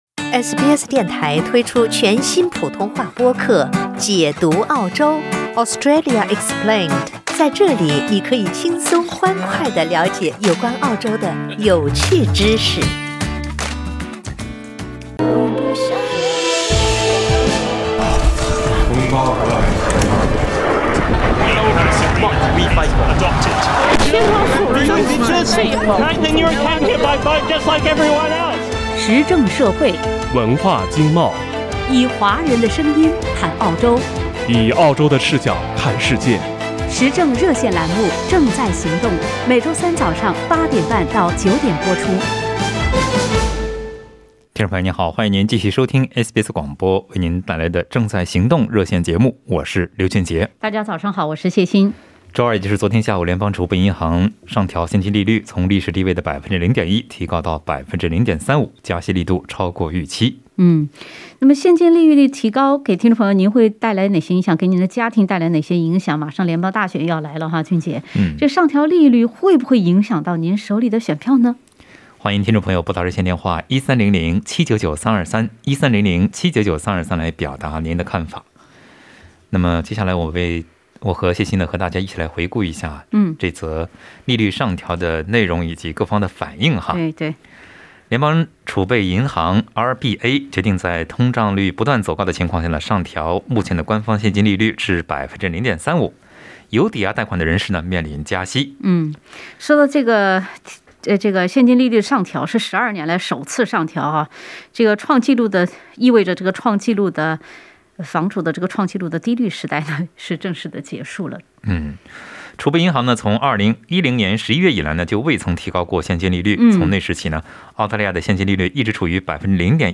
在本期《正在行动》热线节目中